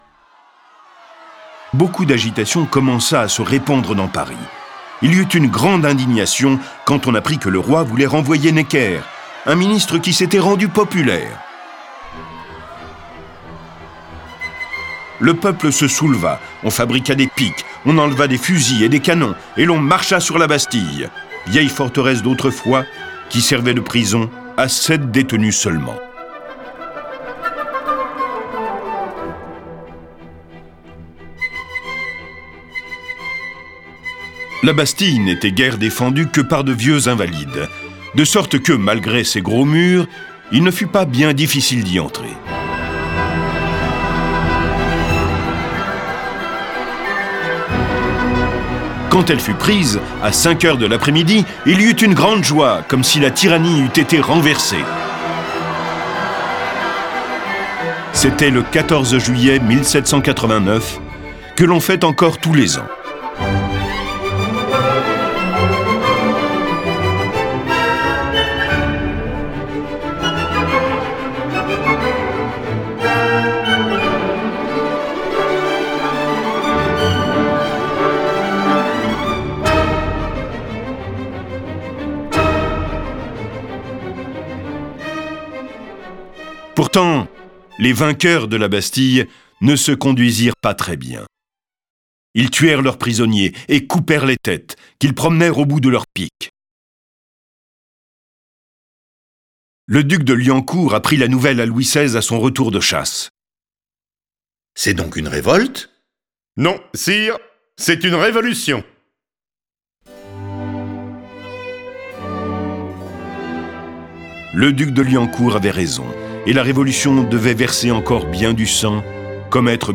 Diffusion distribution ebook et livre audio - Catalogue livres numériques
En quelques mois, l’Ancien Régime s’effondre dans le sang et la terreur. Cette version sonore de la vie du Roi Martyr est animée par dix voix et accompagnée de plus de trente morceaux de musique classique.
Le récit et les dialogues sont illustrés avec les musiques de Bach, Bécourt, Charpentier, Corelli, Delibes, Dvorak, Gebauer, Grieg, Lully, Mahler, Marcello, Mozart, Pachelbel, Pergolèse, Rameau, Rossini, Tchaïkovski, Telemann, Wagner.